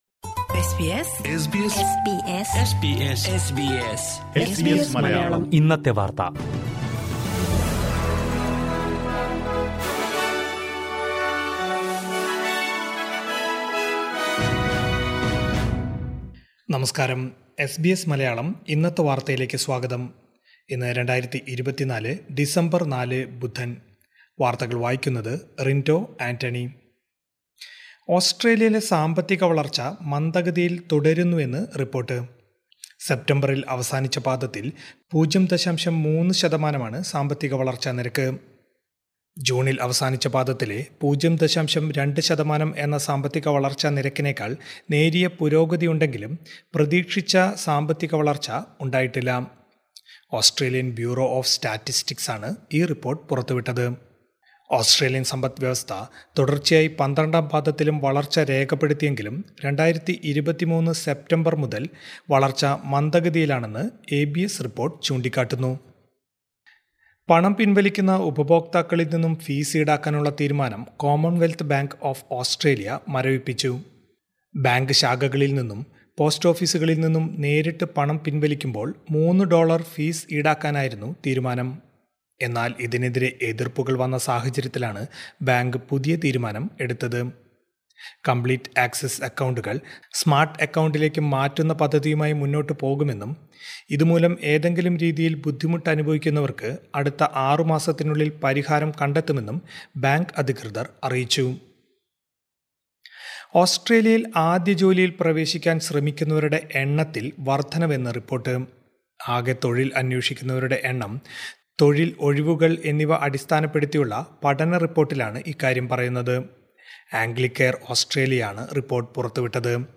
2024 ഡിസംബര്‍ നാലിലെ ഓസ്‌ട്രേലിയയിലെ ഏറ്റവും പ്രധാന വാര്‍ത്തകള്‍ കേള്‍ക്കാം.